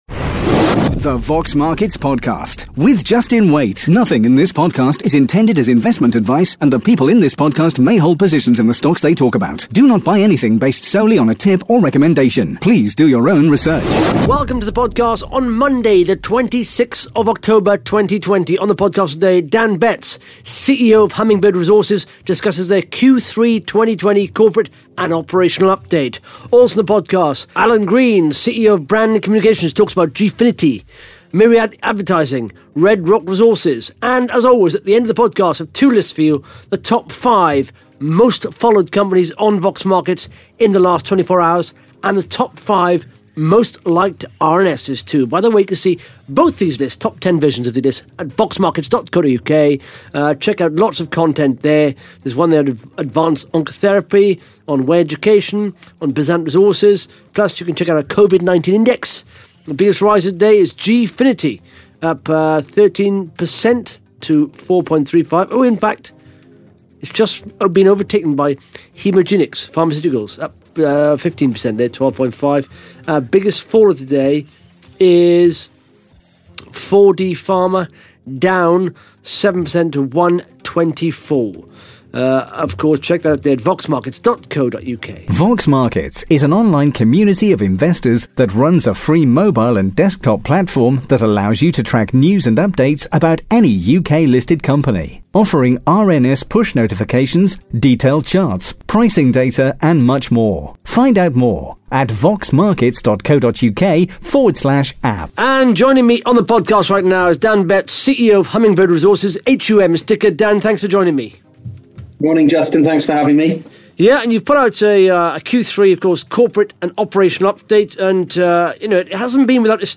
(Interview starts at 12 minutes 1 seconds)